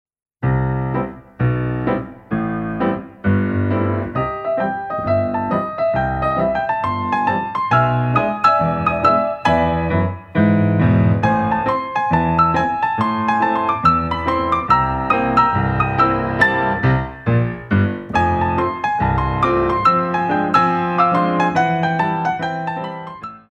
Dégagés